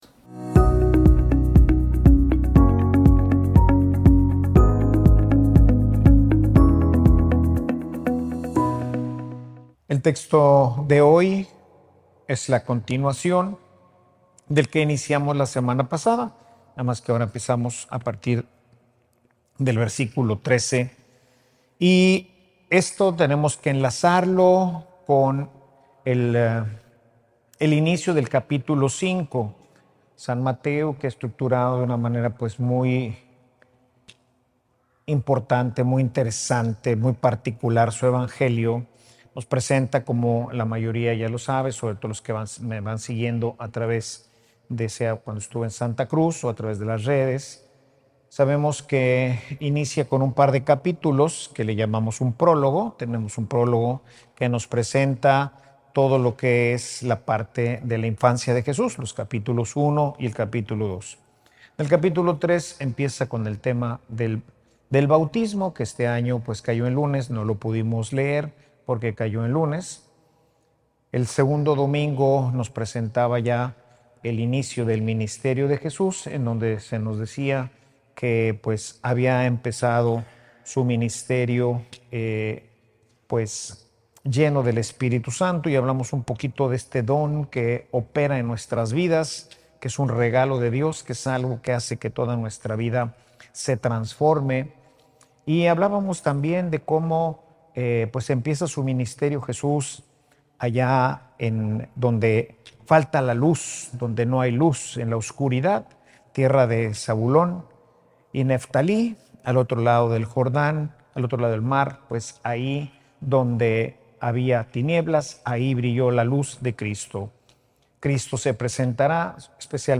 Homilia_la_palabra_es_nuestra_sal_y_luz.mp3